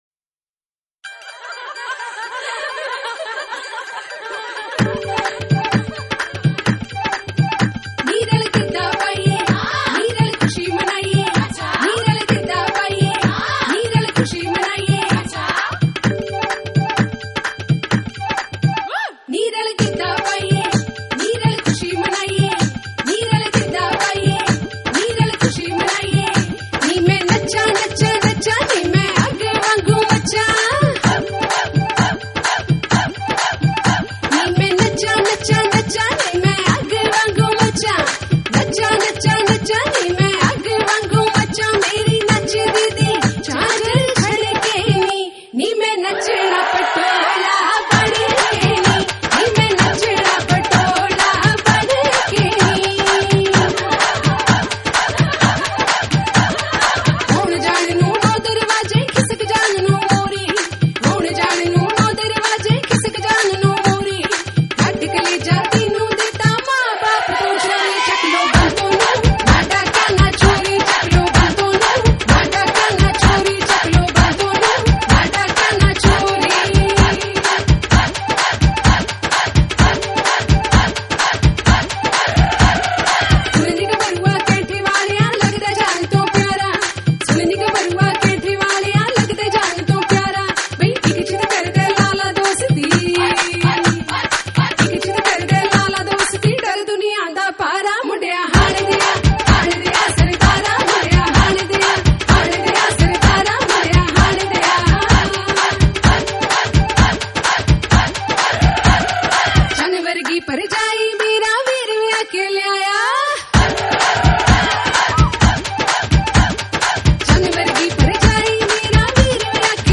64 Kbps Low Quality